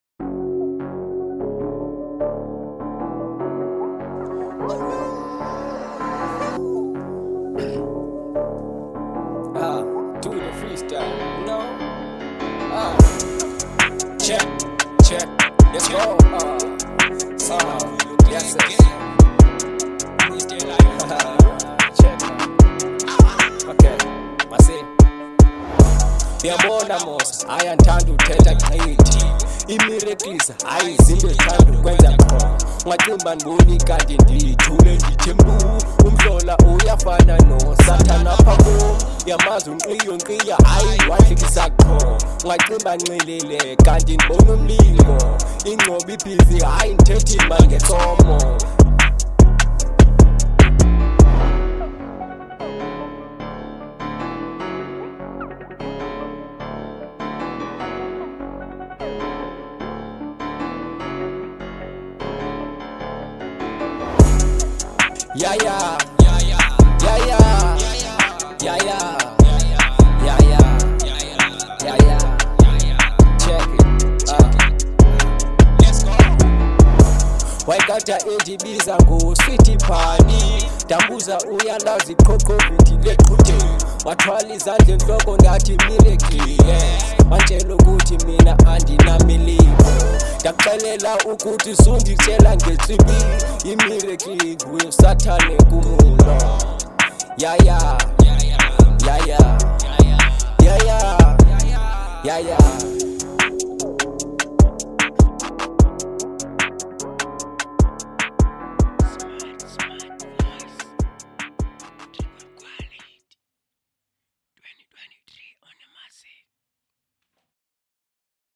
01:58 Genre : Hip Hop Size